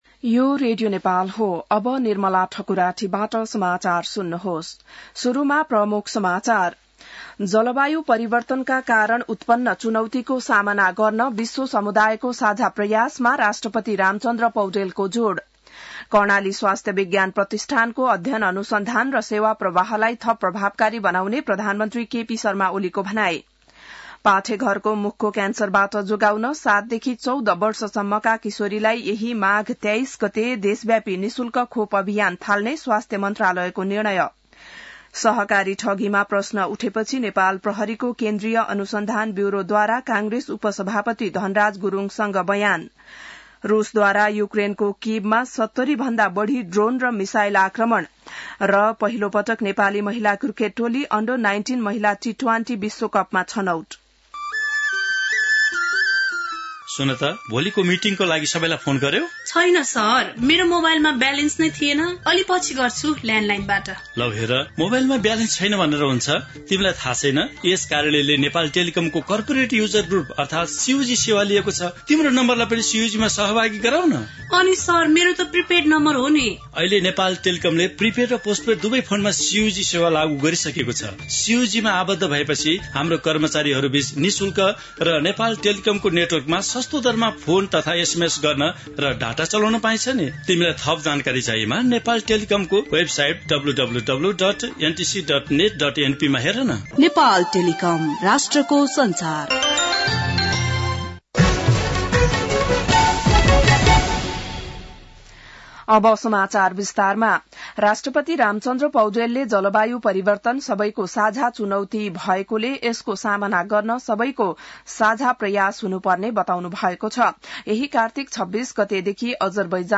बिहान ७ बजेको नेपाली समाचार : ३० कार्तिक , २०८१